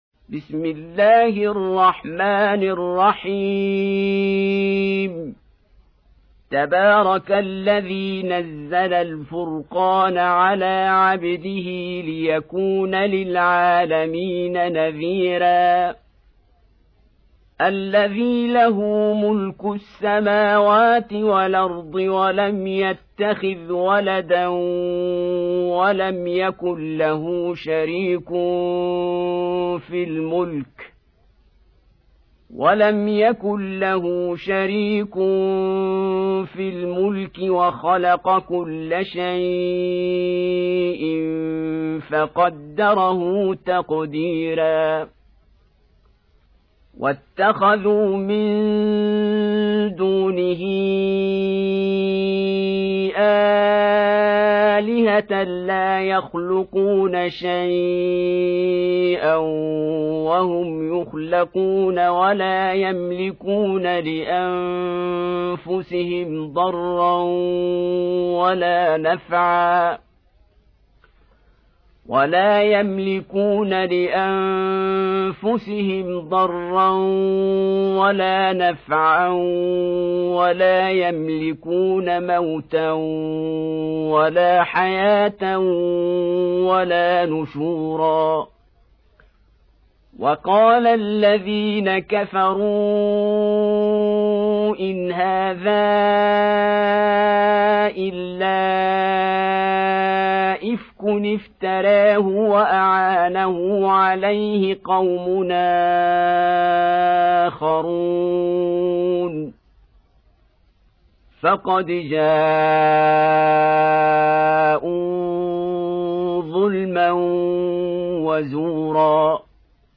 25. Surah Al-Furq�n سورة الفرقان Audio Quran Tarteel Recitation Home Of Sheikh Abdul-Basit Abdul-Samad :: الشيخ عبد الباسط عبد الصمد